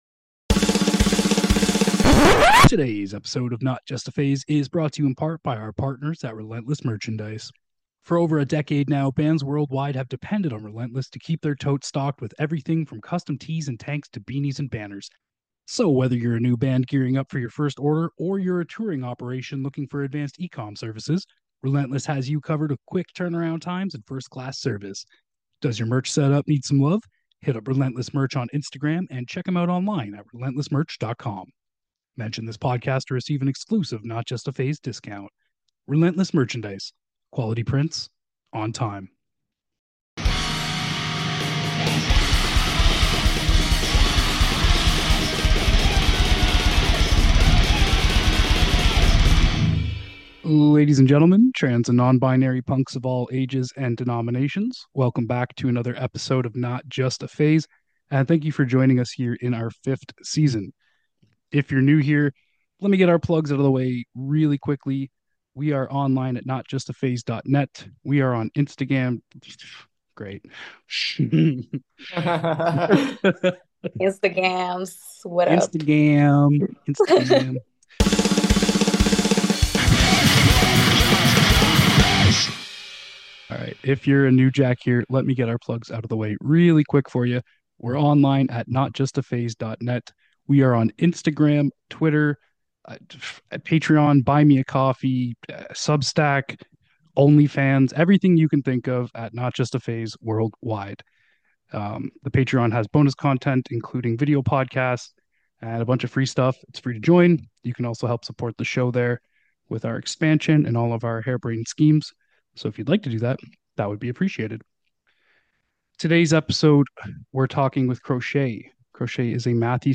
A punk show interviewing artists and labels that produce various music genres.